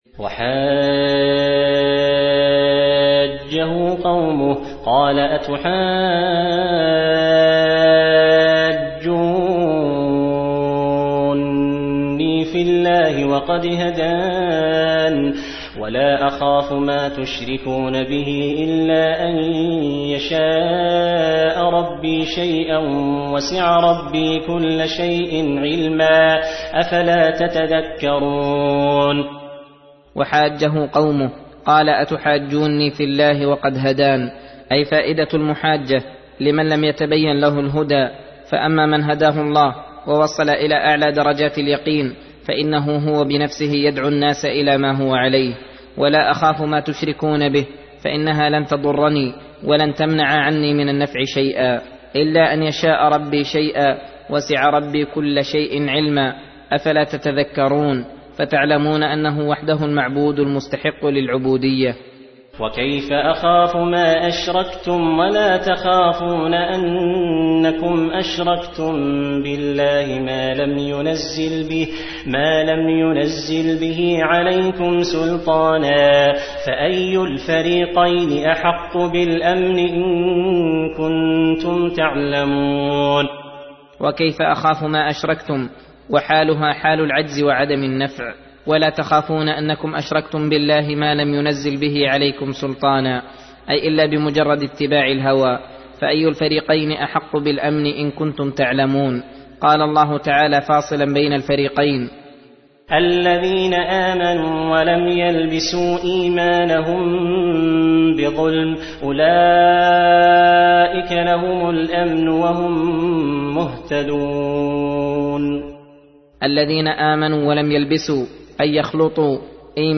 درس (29) : تفسير سورة الأنعام: (80-94)